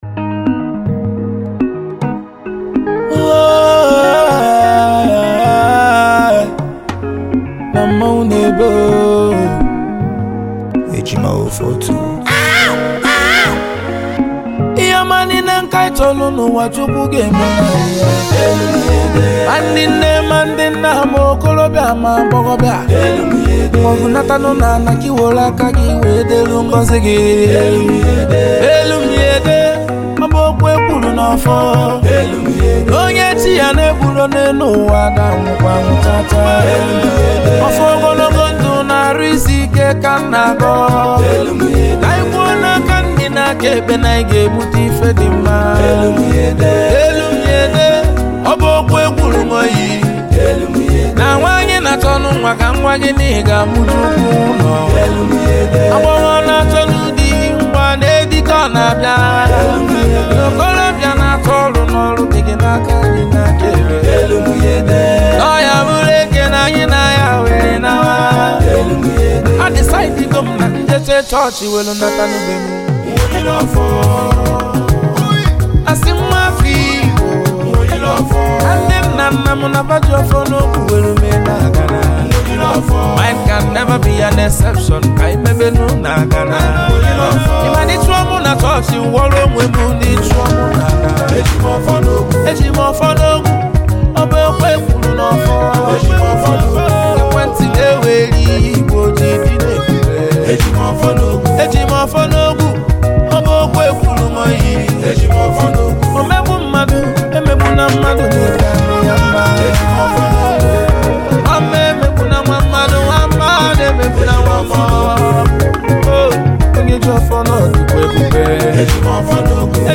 October 15, 2024 admin Highlife Music, Music 0
Nigerian Highlife Music Duo